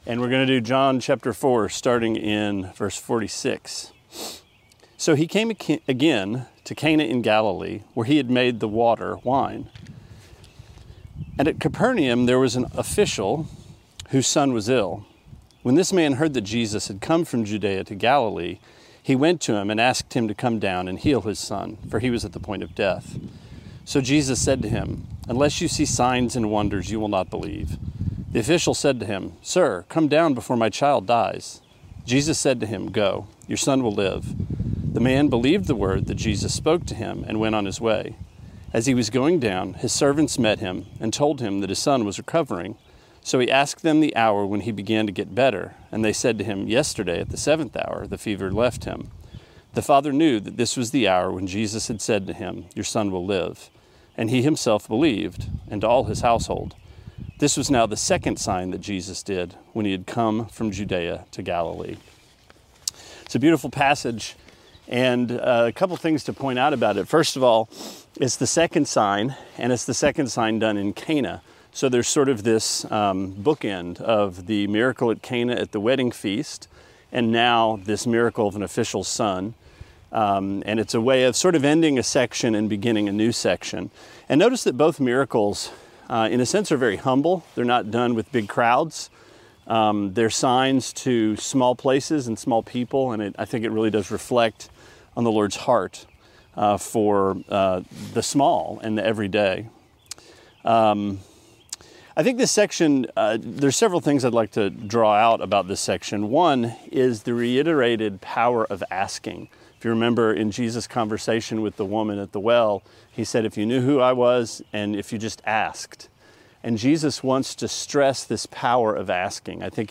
Sermonette 4/26: John 4:46-54: Your son lives!